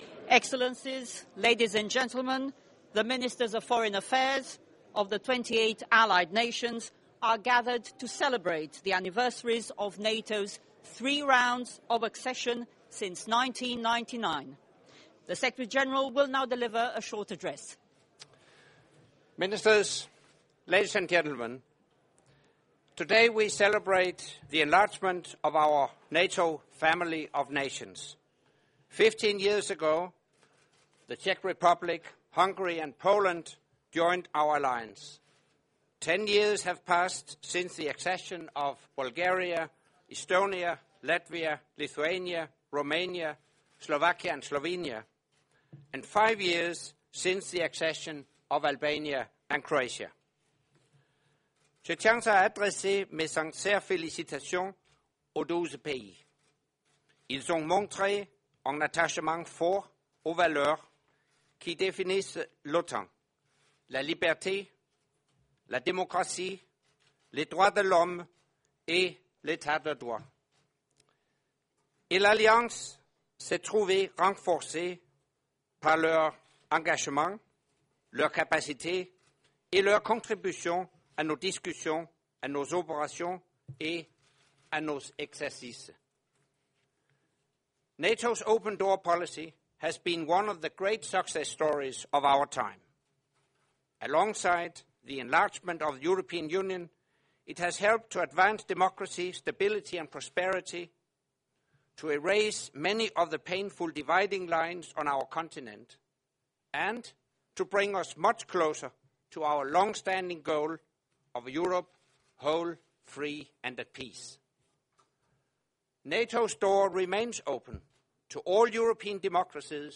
Remarks by NATO Secretary General Anders Fogh Rasmussen at the ceremony to mark the NATO Enlargement anniversaries
Address by the NATO Secretary General at the Ceremony to mark the NATO Enlargement Anniversaries